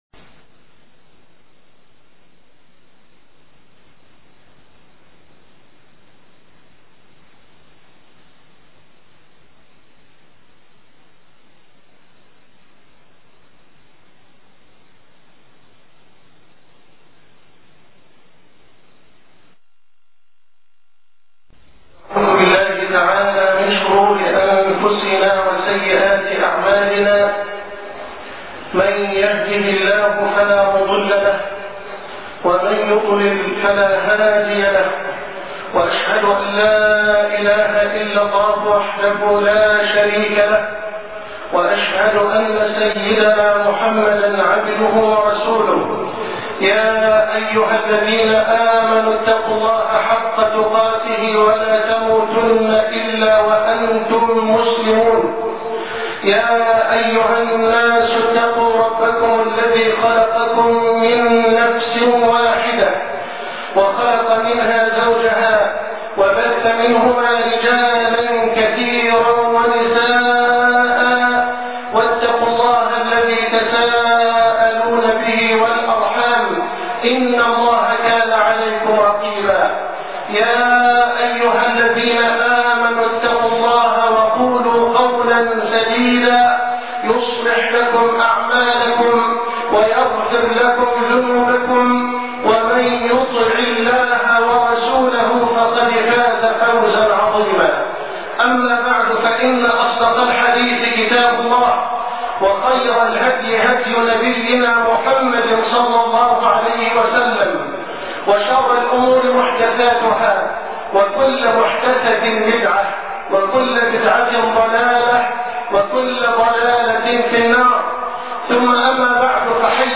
وقفات مع الصحابة ( خطبة الجمعة ) - فضيلة الشيخ محمد حسان